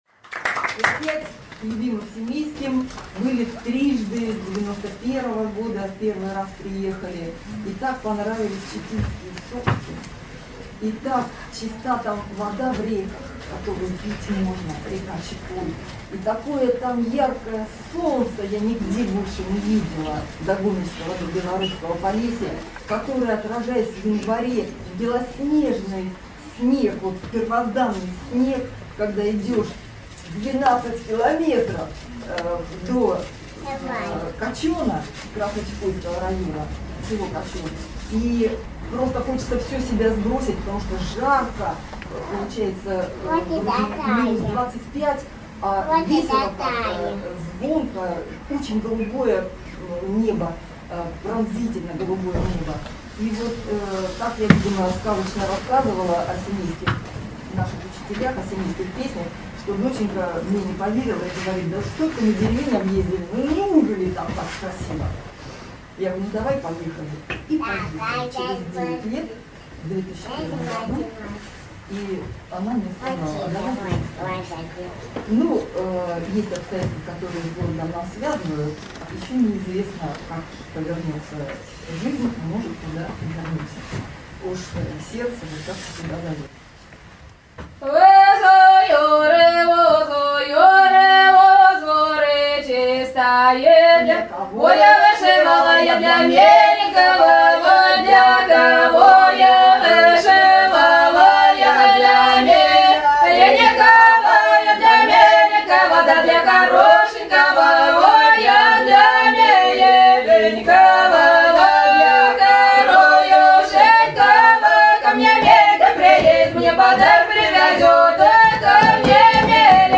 Концерты IX международного форума «Живая традиция-2014»